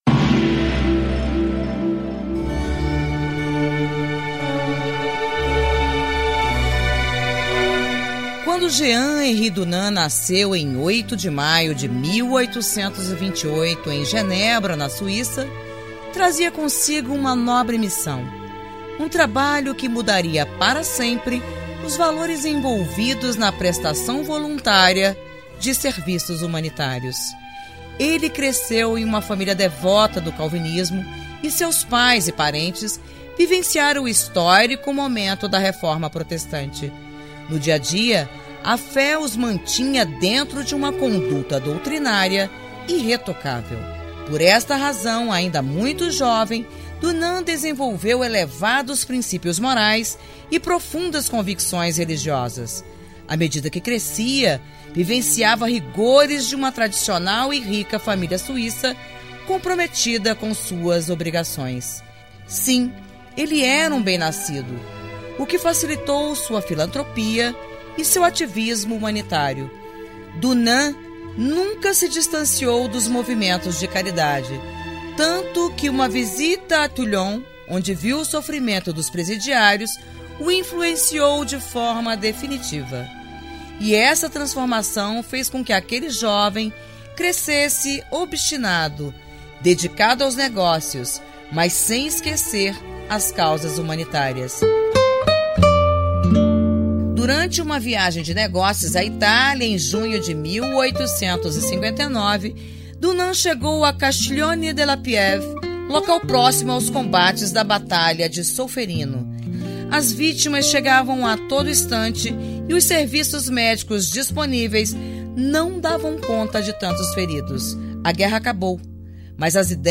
História Hoje: Programete sobre fatos históricos relacionados a cada dia do ano.